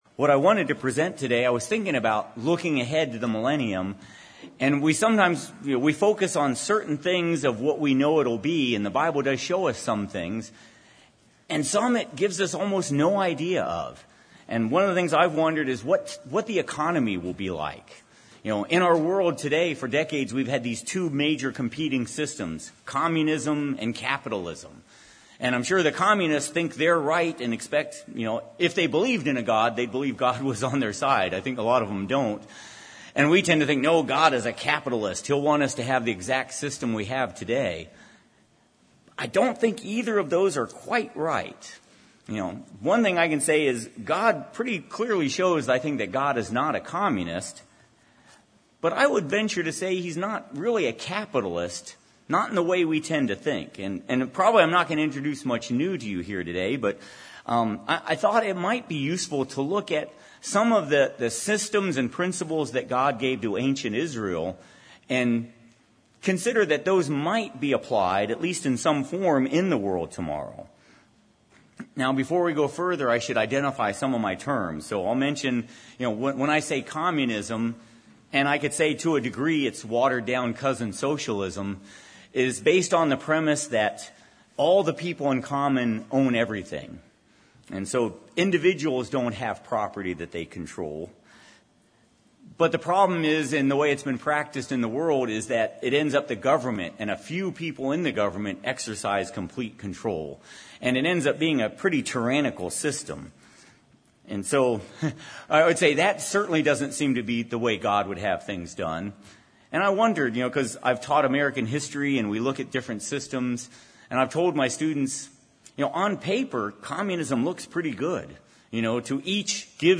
This sermon was given at the Cincinnati, Ohio 2021 Feast site.